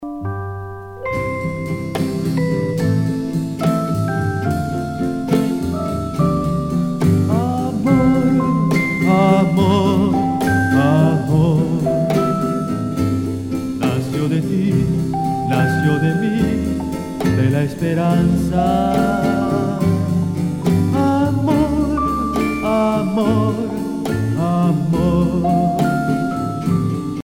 danse : slow
Pièce musicale éditée